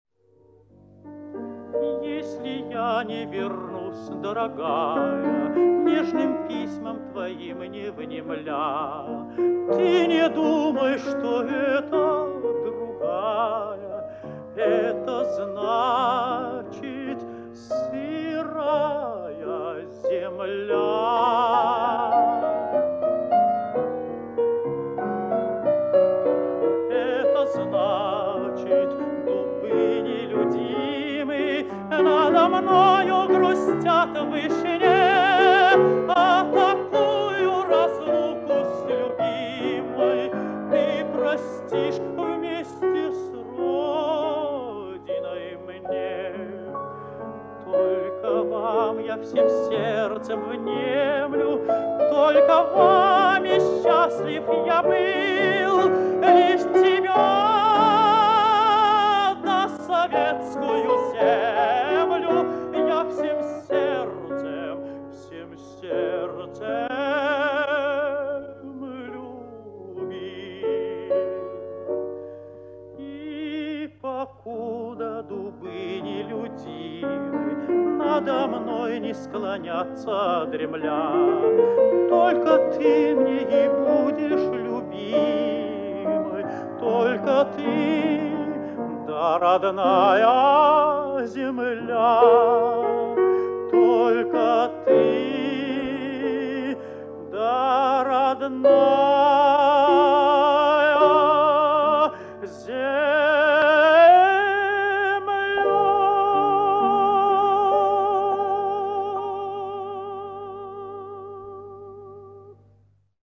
Прекрасный романс